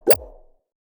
UI_SFX_Pack_61_8.wav